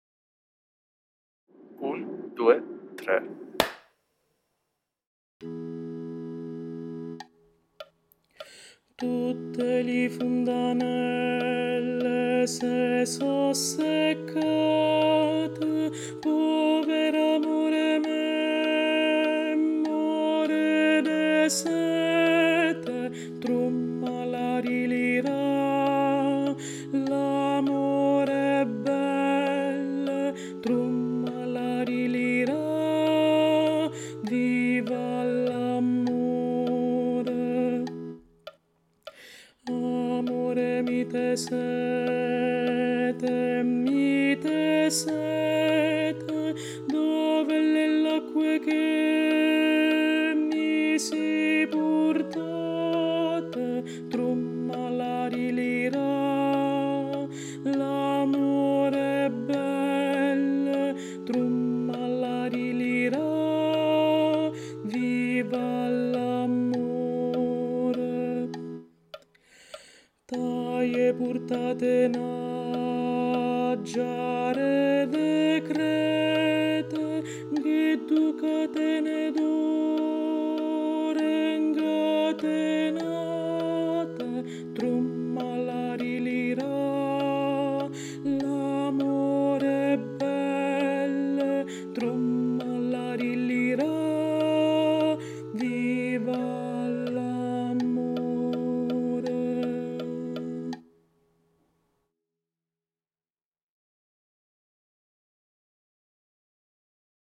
🎧 Voce guida
Tenori